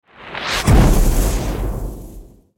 دانلود صدای آتش 22 از ساعد نیوز با لینک مستقیم و کیفیت بالا
جلوه های صوتی